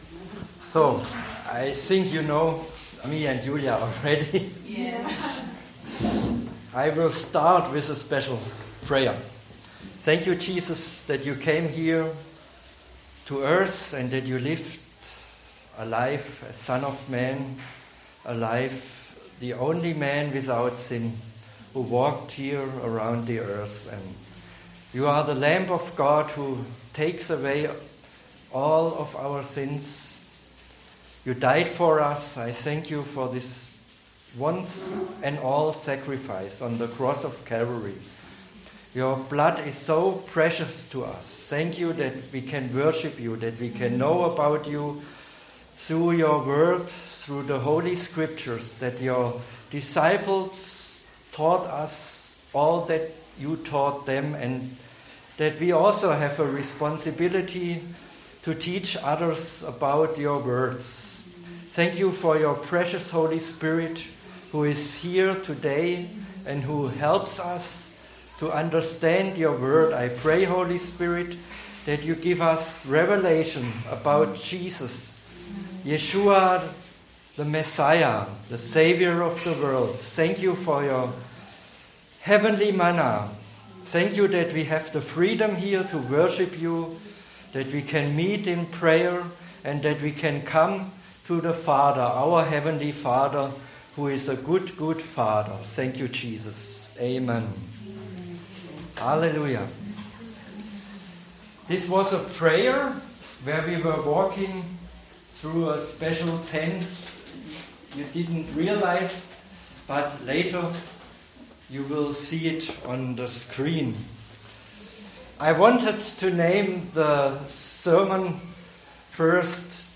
I spoke in front of a small Filipino congregation in Esslingen about my favourate subject the symbolism of the tabernacle and the temple.
sermontabernaclewinchurch.mp3